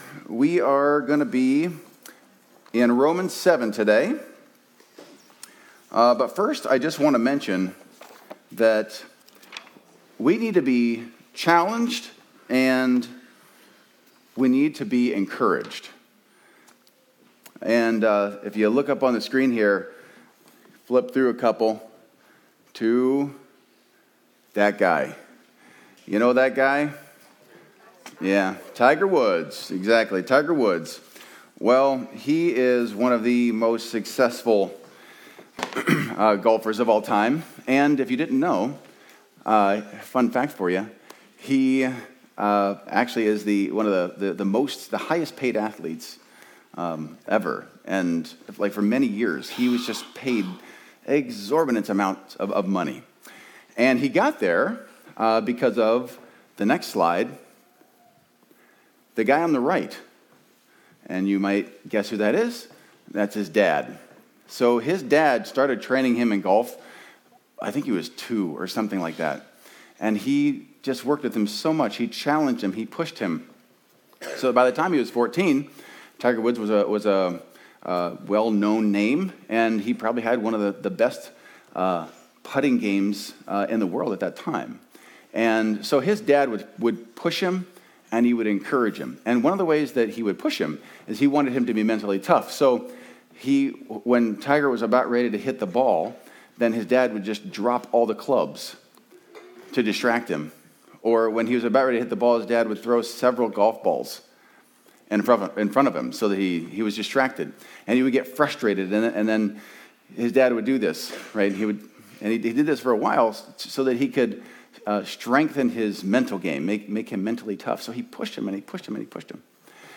Video Audio Download Audio Home Resources Sermons Do you live by the letter of the law or the Spirit of the law?